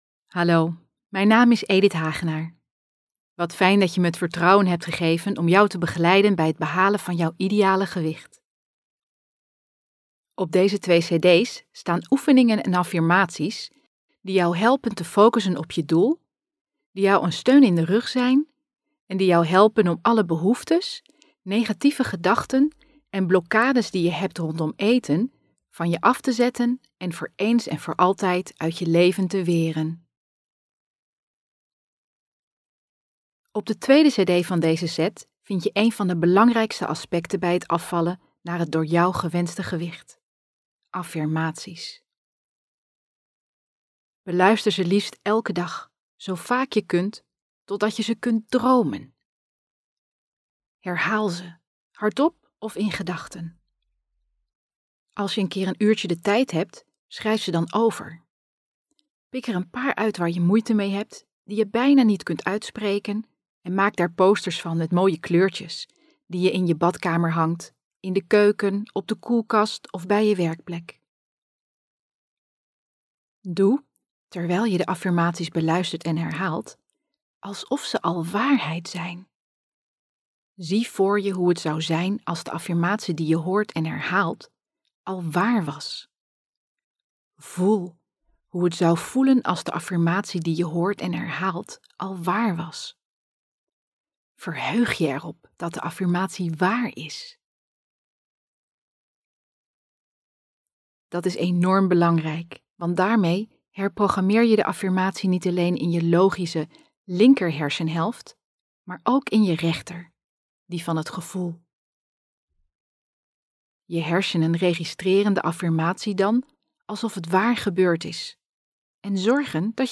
Circa 120 minuten affirmaties, gesproken tekst, meditaties en simpele oefeningen.